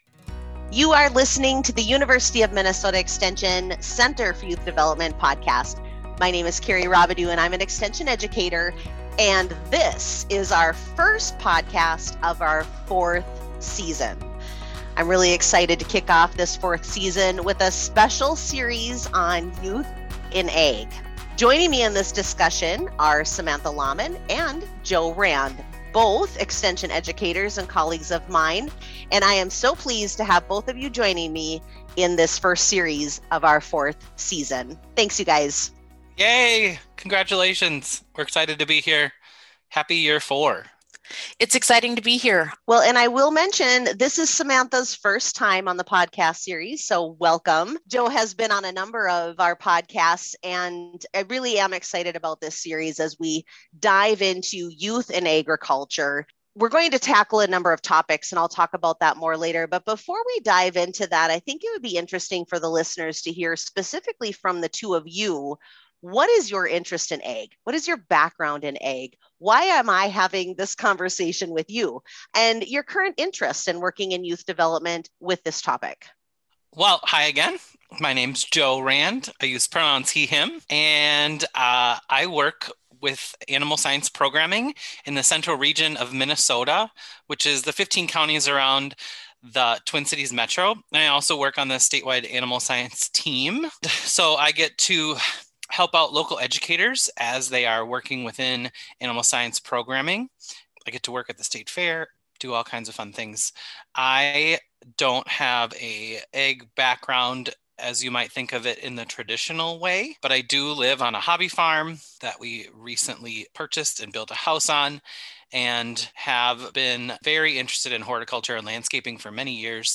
a conversation about current trends in Ag and how Generation Z youth will impact the workforce.